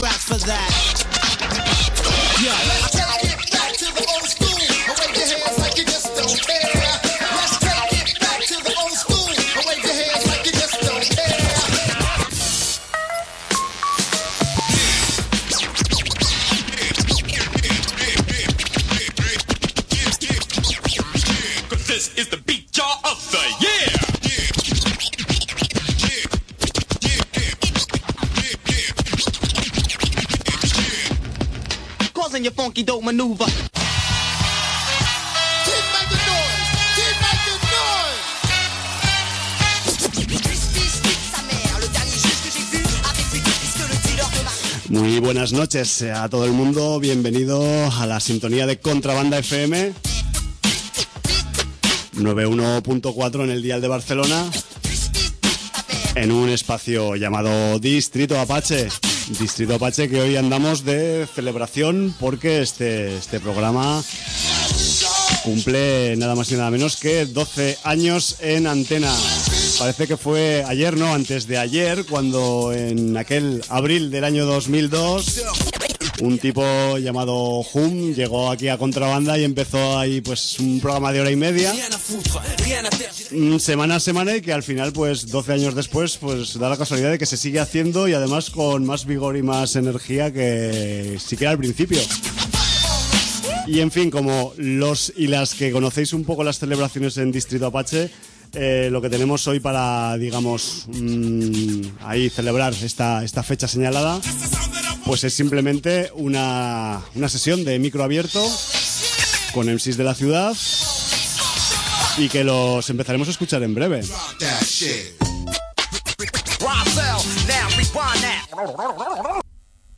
sesión de rimas en directo
la participación de más de 20 MCs